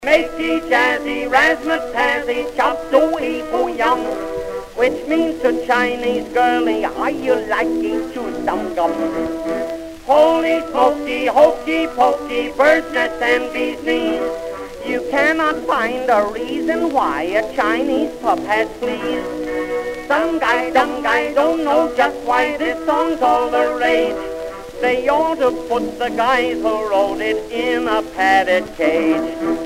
Orch. acc.
stereotypical Chinese accents